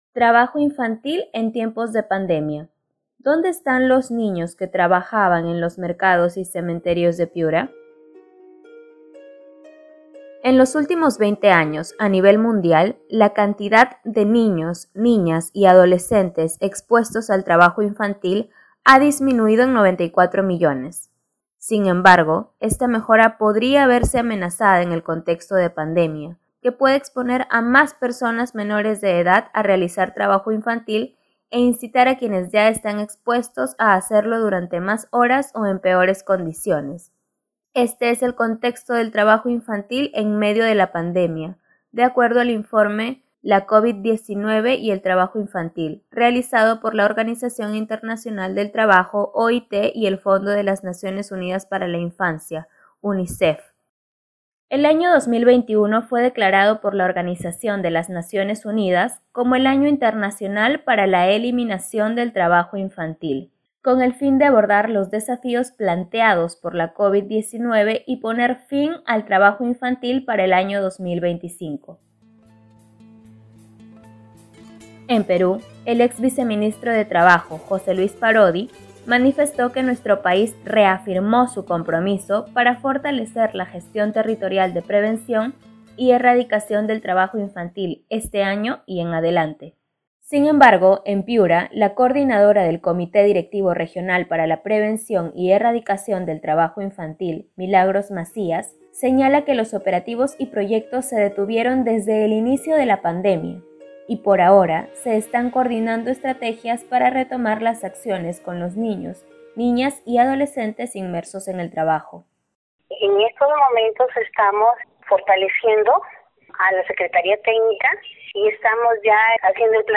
Un informe